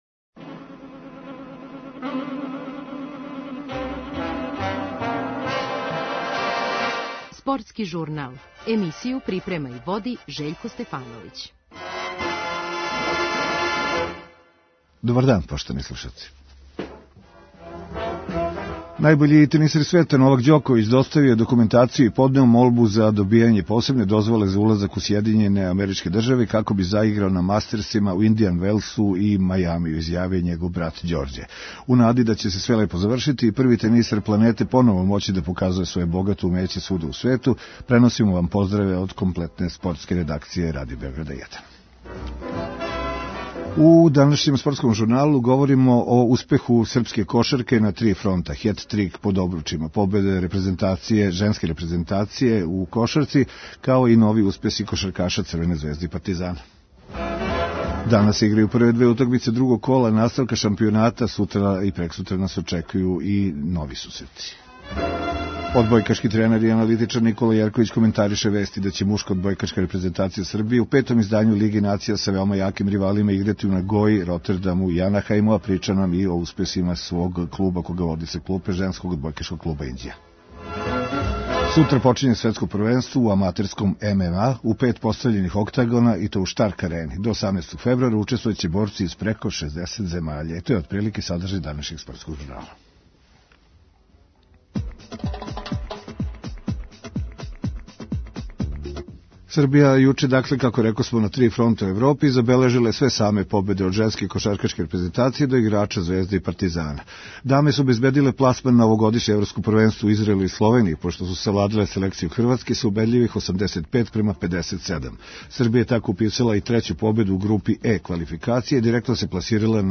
О европским утакмицама наших клубова разговарамо са кошаркашким тренером